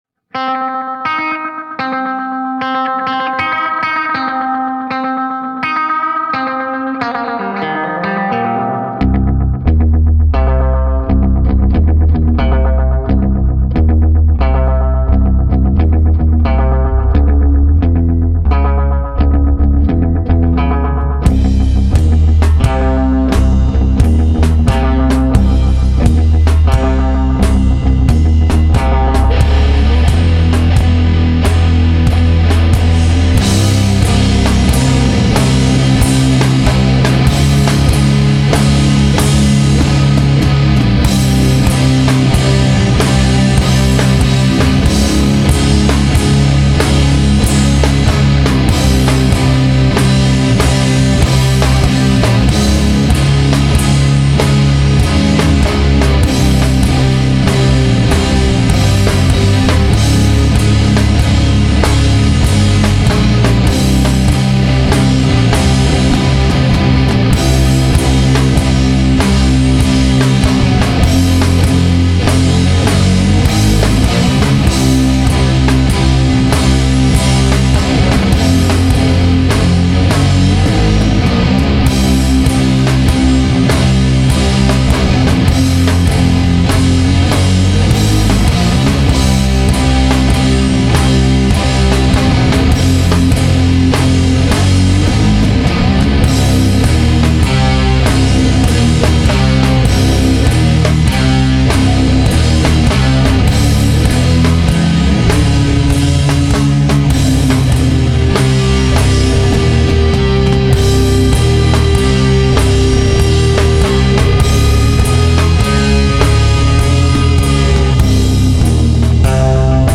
Heavy metal
Experimental
Prog rock